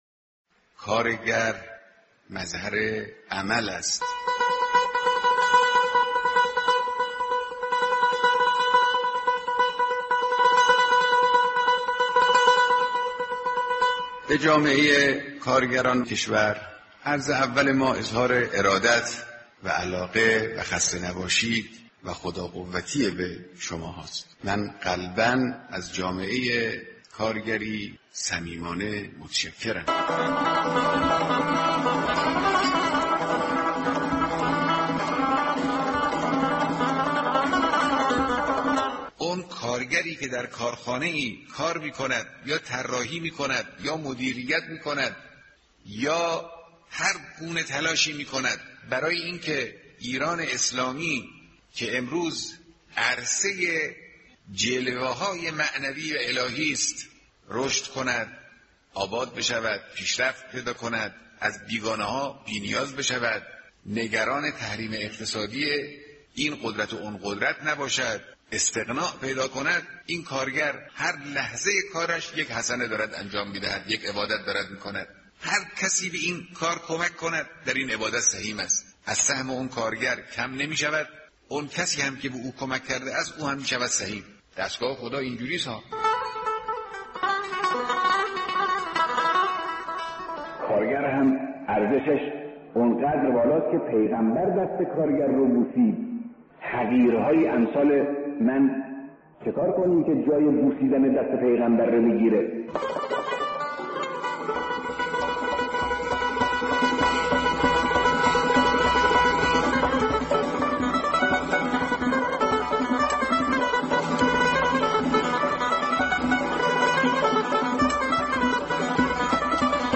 كليپ صوتی از بيانات رهبر انقلاب به مناسبت روز کارگر